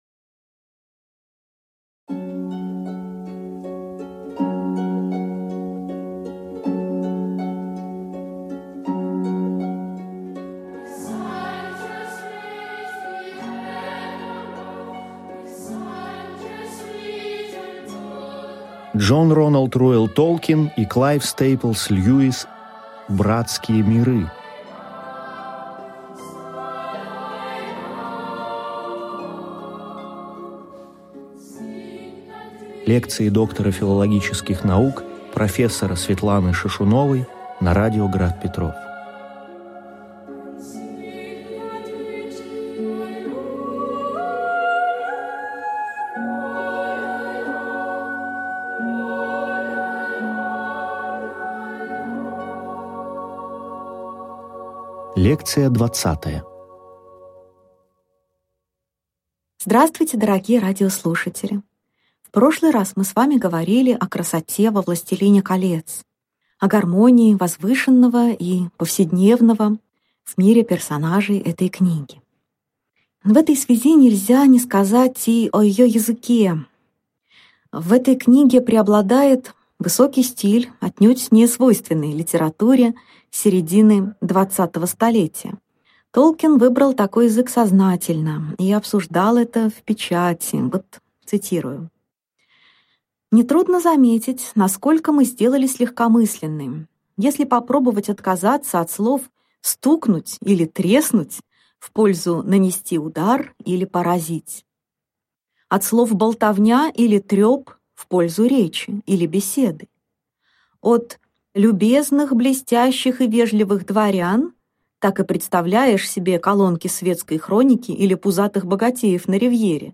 Аудиокнига Лекция 20. Дж.Р.Р.Толкин. «Властелин Колец» и реальная история Европы | Библиотека аудиокниг